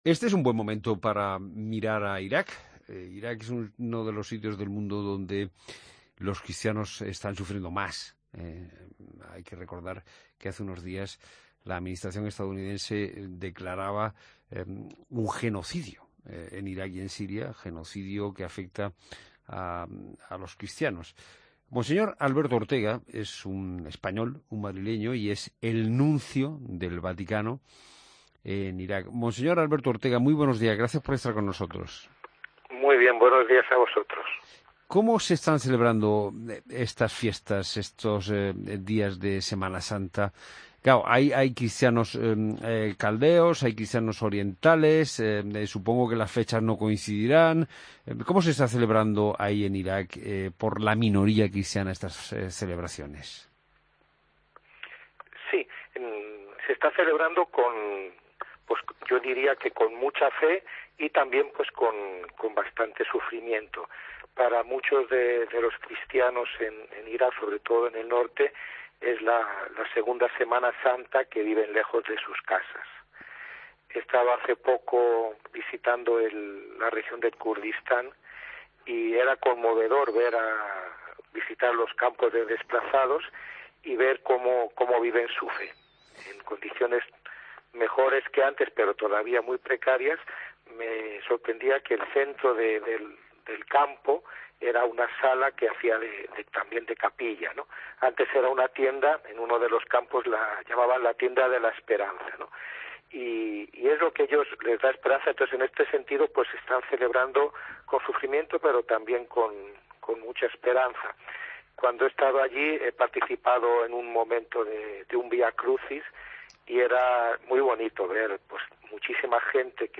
AUDIO: Escucha la entrevista a Monseñor Ortega, nuncio apostólico en Irak y Jordania, en La Mañana Fin de Semana de COPE